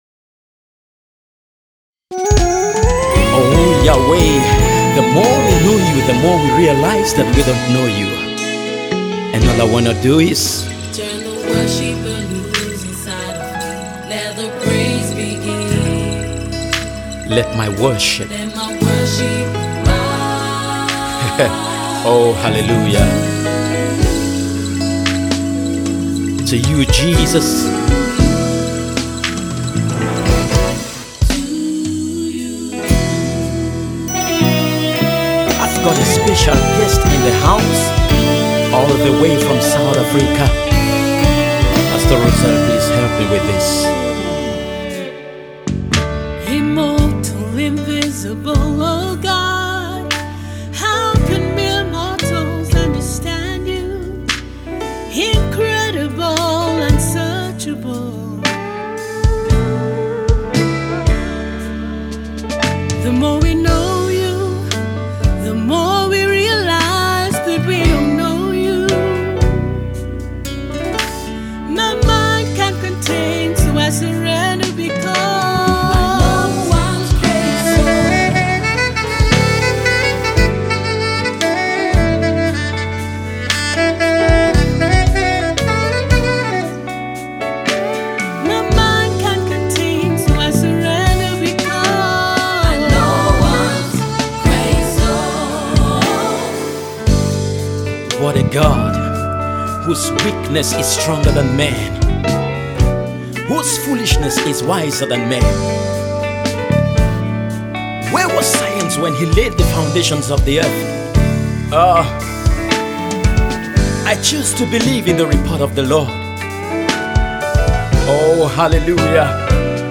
Uprising Cameroonian based Gospel Singer